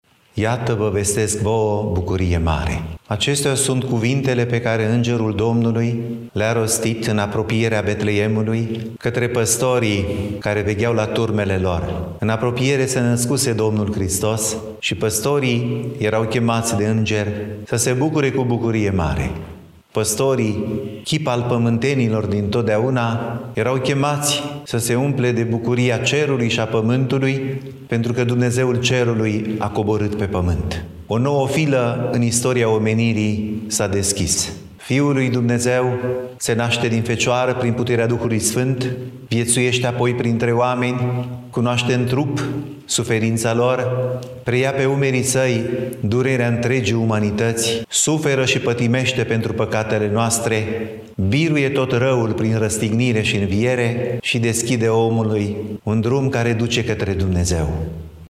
Despre semnificaţia zilei de astăzi, ne vorbeşte mitropolitul Moldovei şi Bucovinei, Înalt Prea Sfinţitul Teofan: